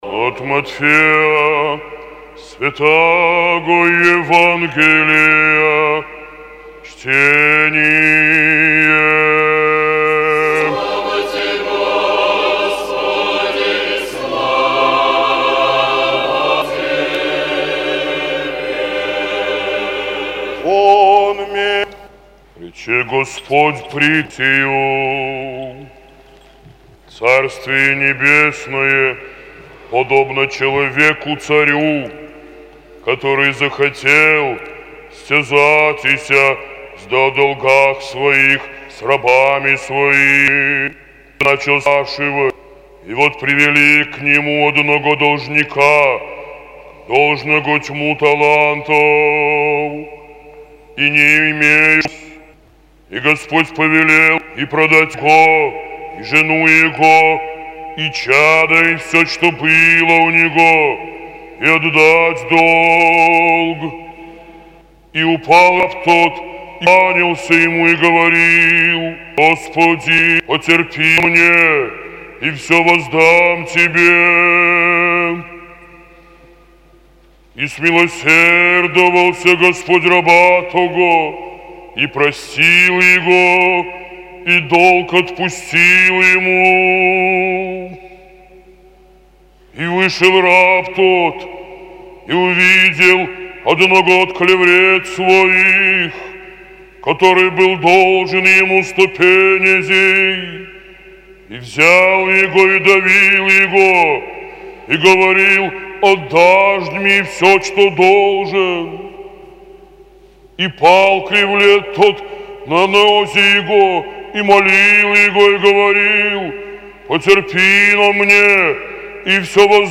Евангельское чтение на литургии Аудио запись